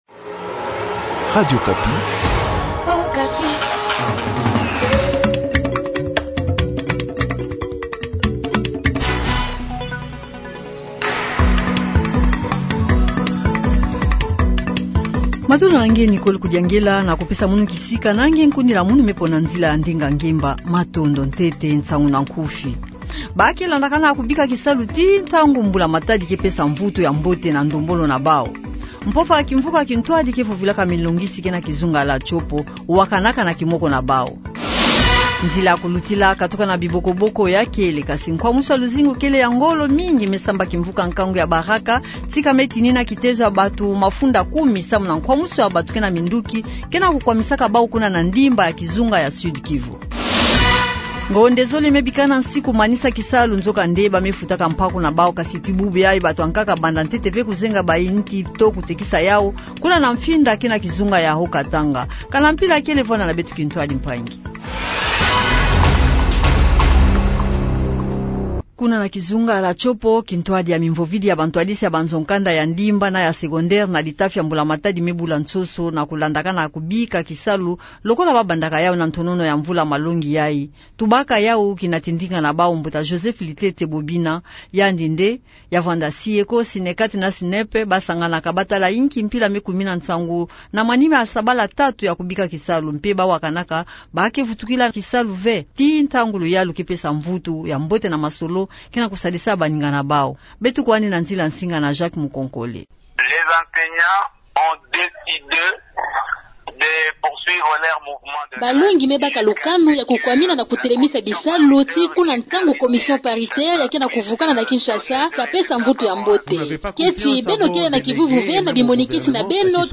JOURNAL SOIR